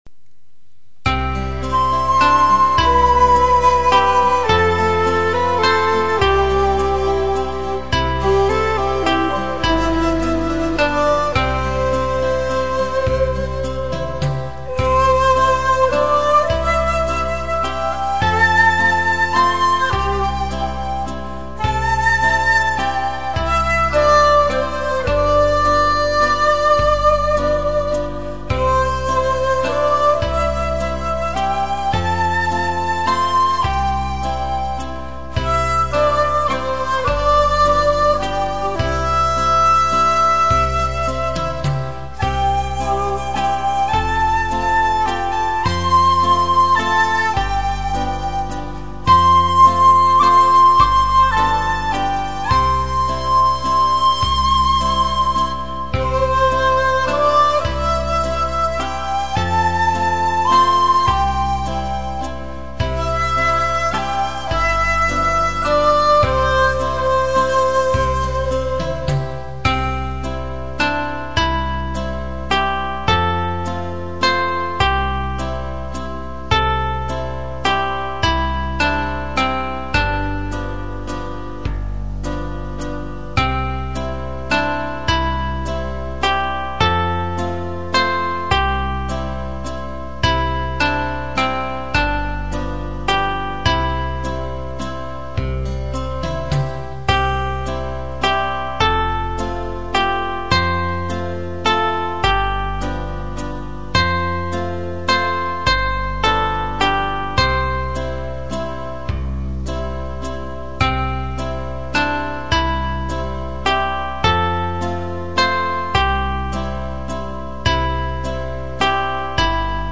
以下の曲には簡単な伴奏が付いていますから、楽しく吹いて正しいリズム感と音感を養ってください。
尺八演奏が、原則的に１コーラス毎に有／無のパターンになっています。
浜千鳥 ３コーラス　尺八/１尺８寸　童謡・唱歌のページに尺八三重奏があります。